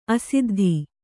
♪ asiddhi